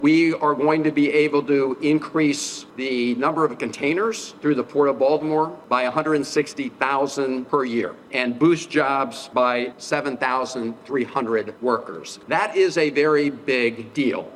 Speaking at the tunnel’s reopening ceremony, Maryland Senator Chris Van Hollen said the improvements mean more business and jobs for the state’s transportation and port industries…